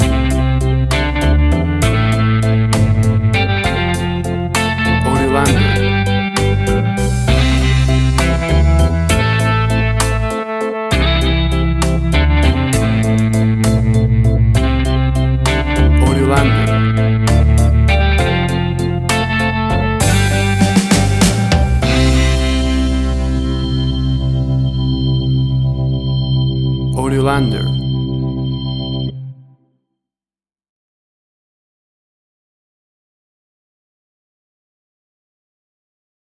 WAV Sample Rate: 16-Bit stereo, 44.1 kHz
Tempo (BPM): 197